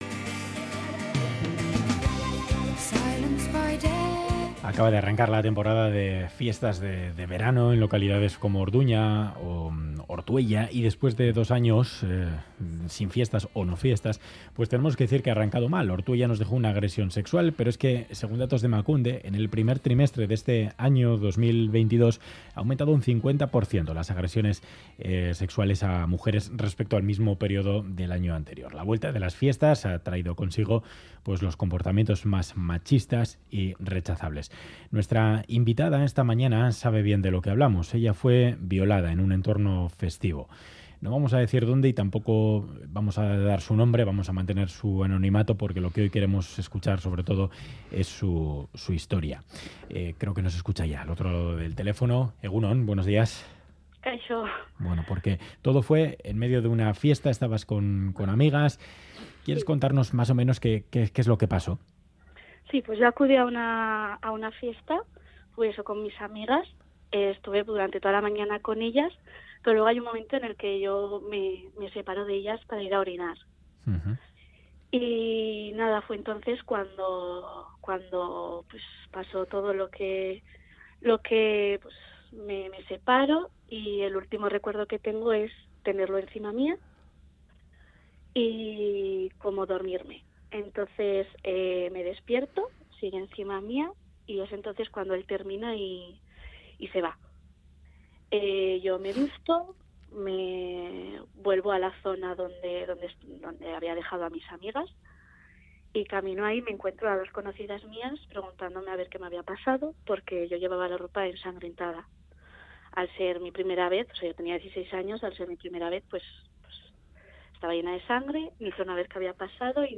Audio: Violaciones en Euskadi, anómina cuenta en Radio Euskadi cómo la violaron.